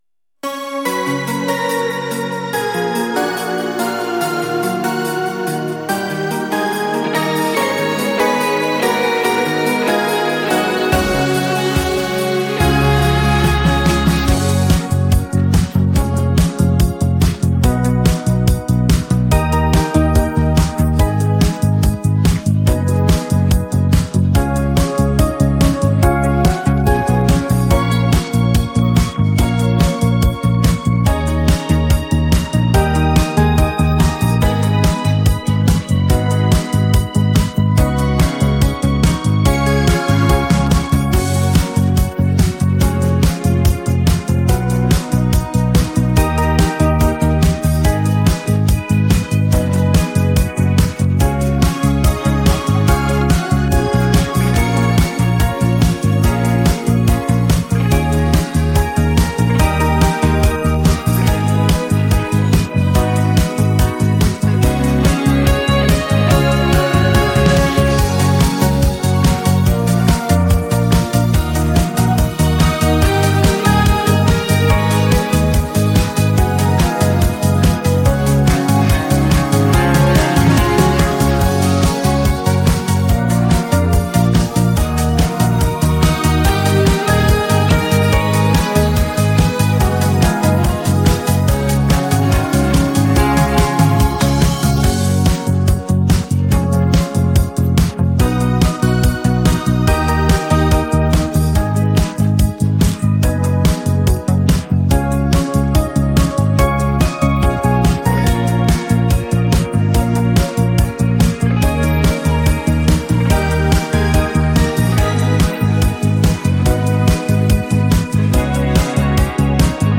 • Категория: Детские песни
минусовка